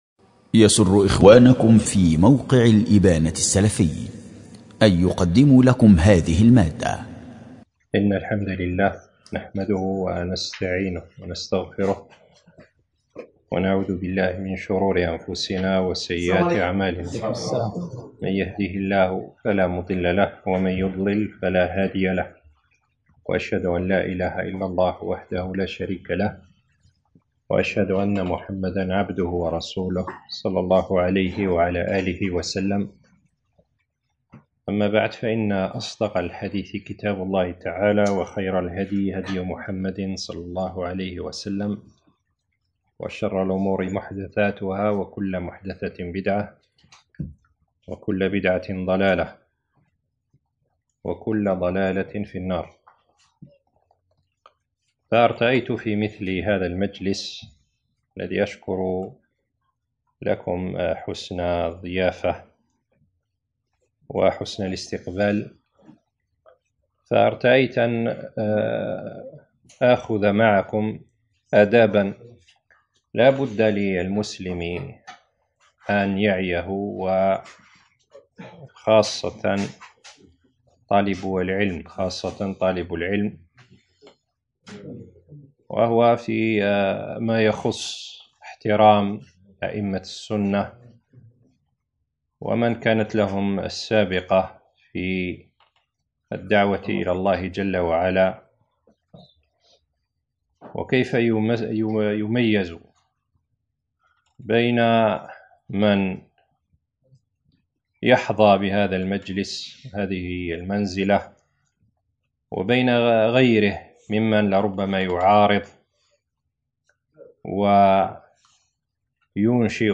🔹للإخوة بمدينة : تيسمسيلت
دروس ومحاضرات